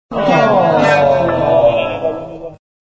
aahhh.mp3